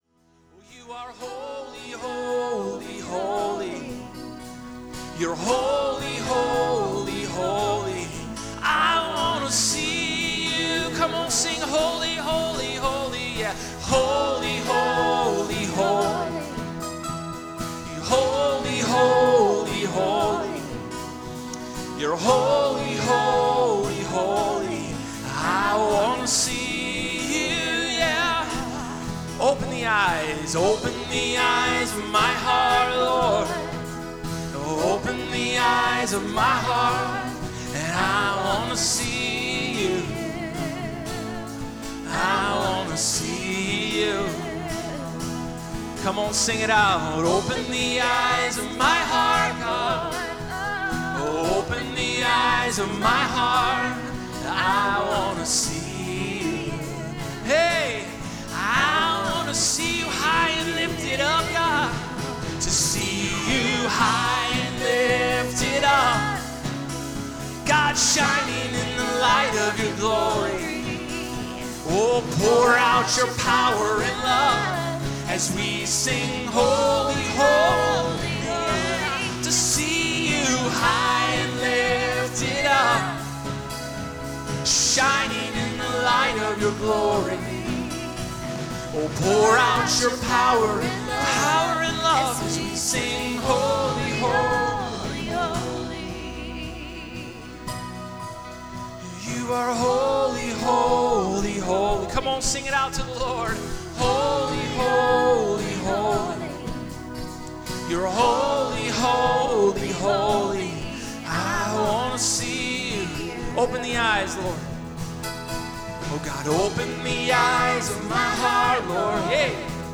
Worship: Dec 19, 2021
~ Our weekly worship services at Marysville Assembly of God features a diversity of songs from various artists ~